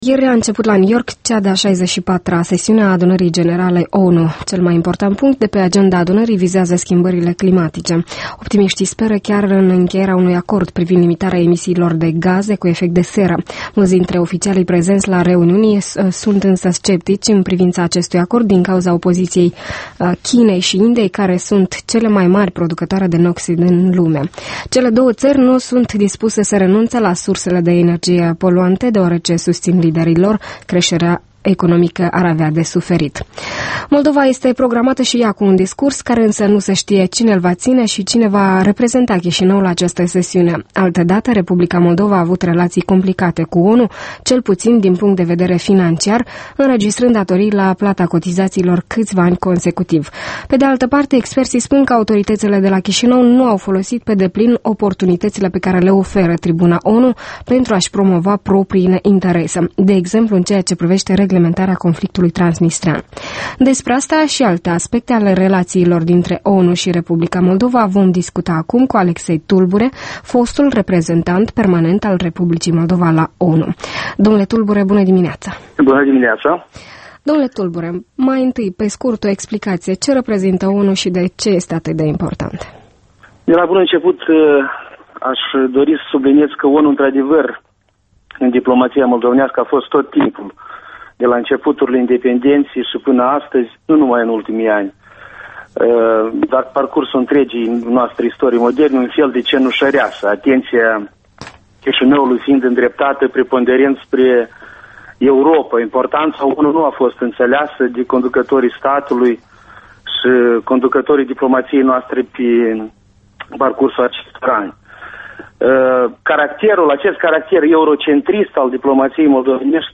Interviu cu Alexei Tulbure: Moldova la ONU